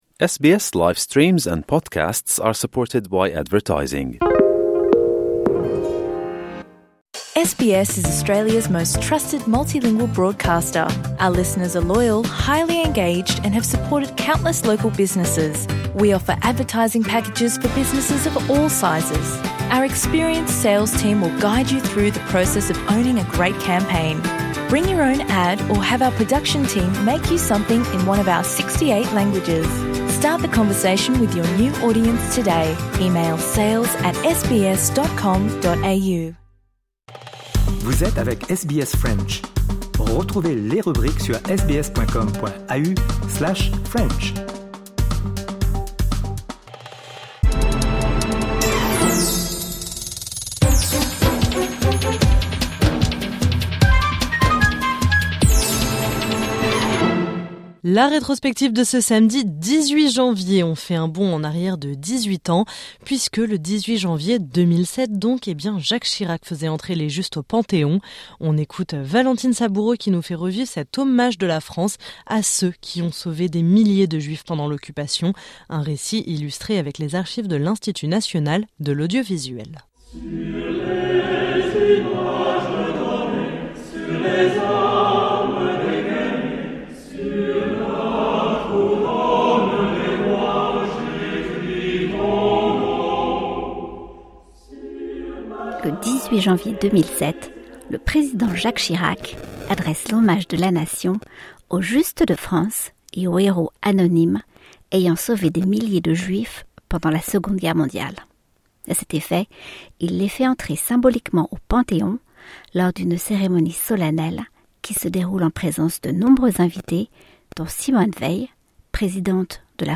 Un récit illustré avec les archives de l’Institut national de l’audiovisuel.